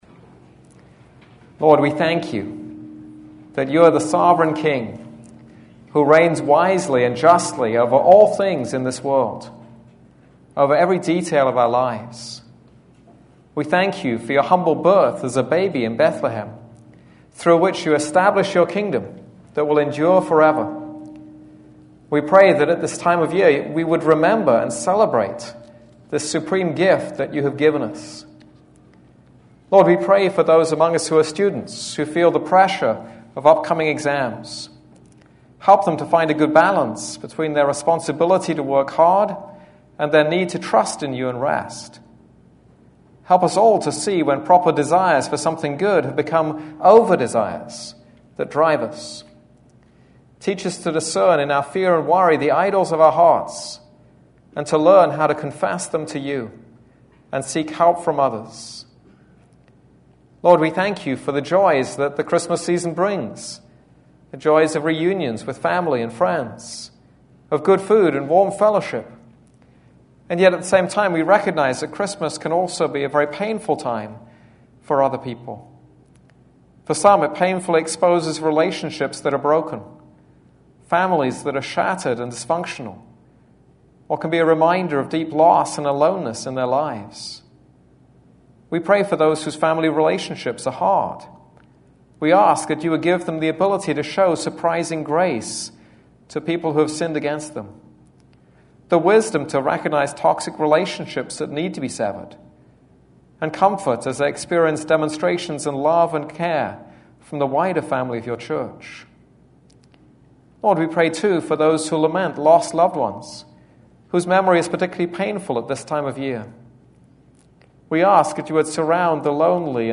This is a sermon on Song of Songs 3:1-5.